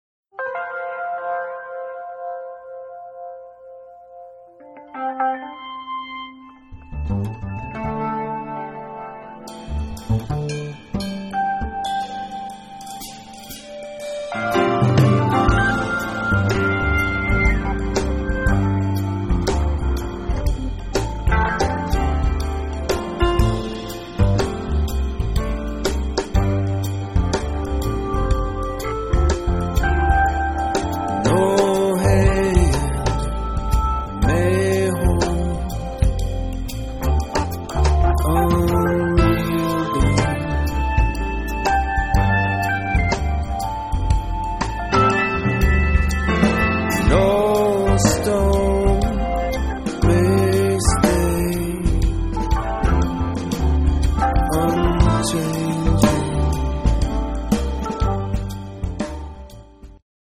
organ and vocals
drums
acoustic and electric bass
Jamband
Jazz